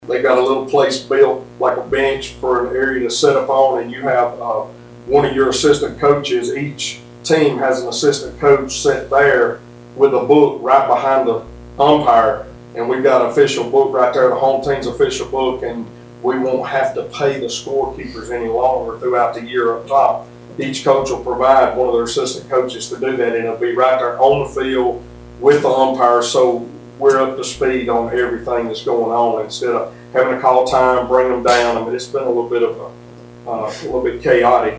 During a recent Centre City Council meeting, Councilman Derrick Wheeler gave an update on improvements that still need to be made to the fields.